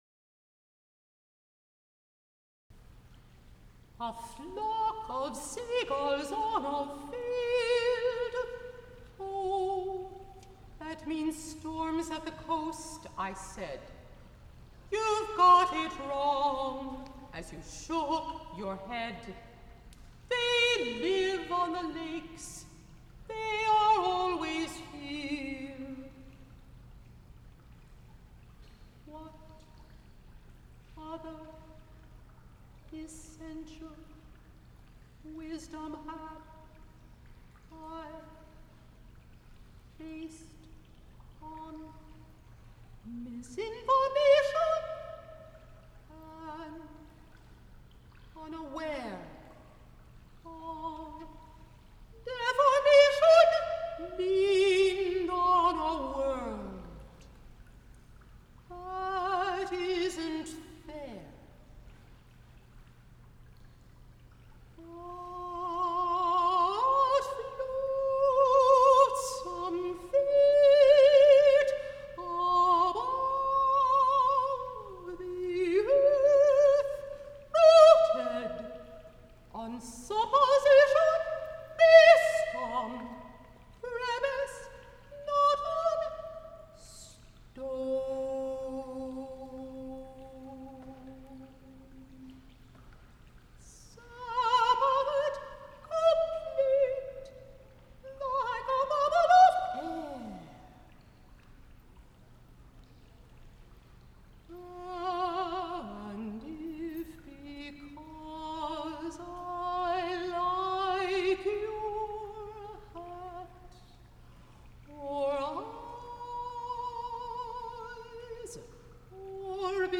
soprano
piano